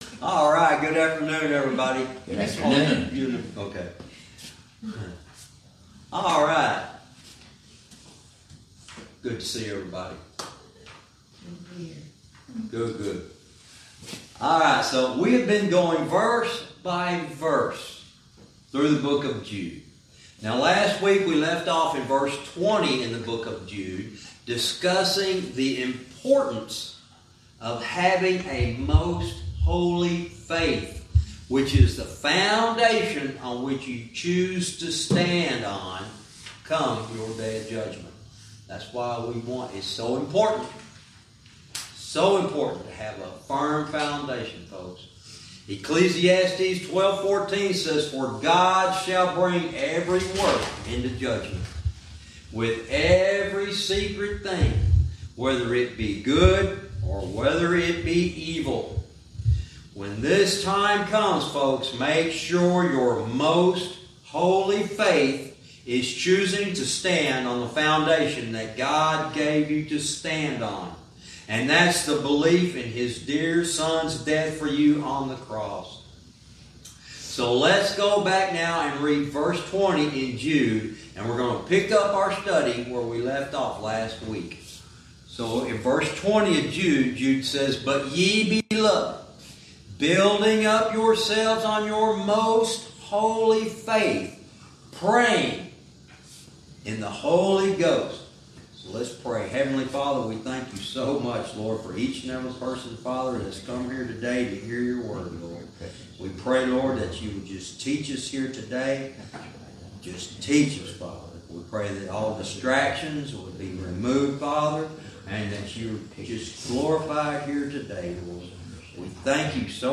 Verse by verse teaching - Jude lesson 92 verse 20